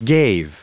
Prononciation du mot gave en anglais (fichier audio)
Prononciation du mot : gave